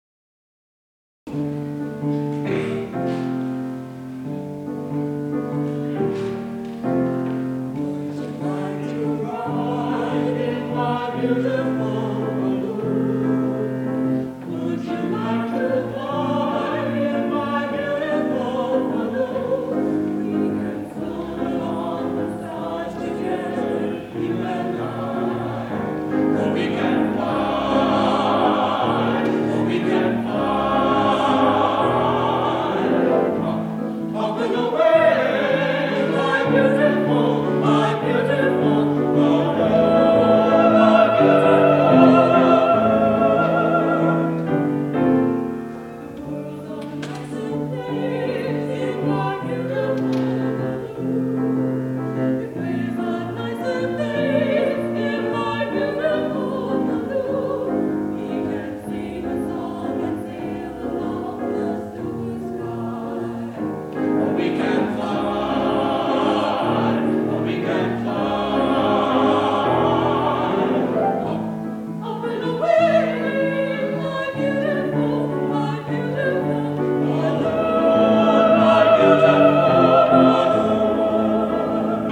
Highland Park, MI, High School Concert Choirs, 1954-1969
CD for the 1969 Spring Concert